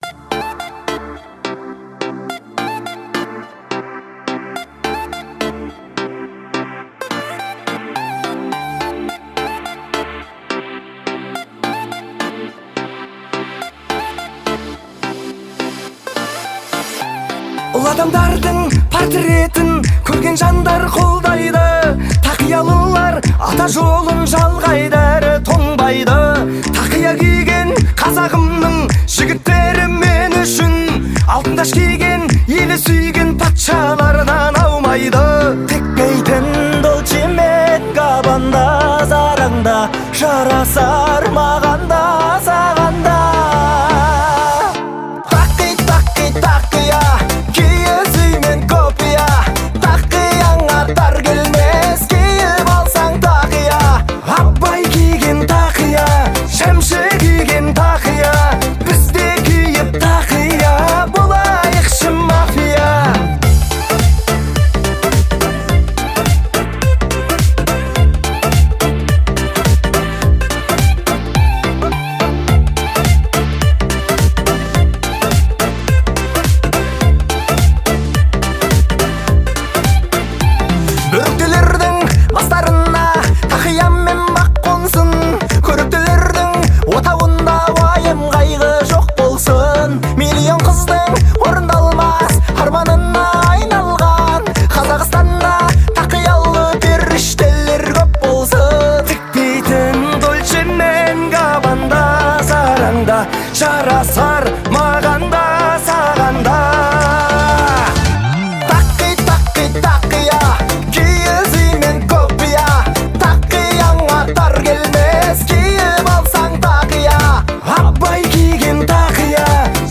это яркая композиция в жанре казахской поп-музыки